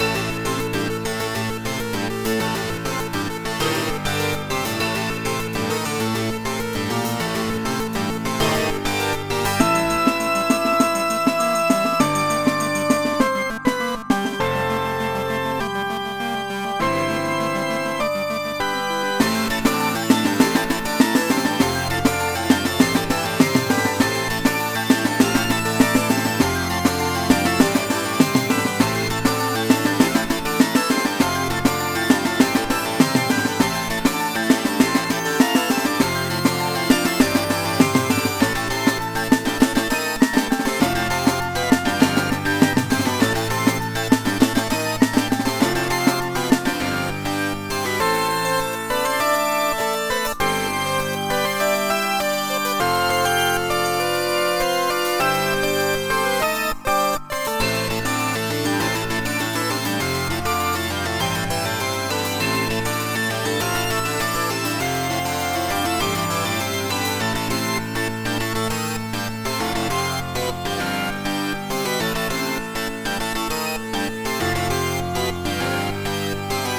A small combatish piece made using some previously used melodies.